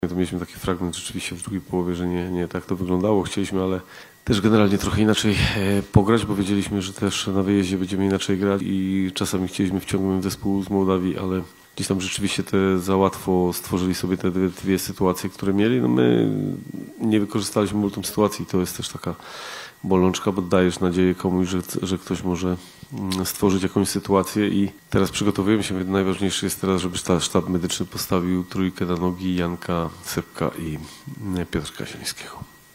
O spotkaniu i nadchodzącym rywalu mówił trener biało-czerwonych- Michał Probierz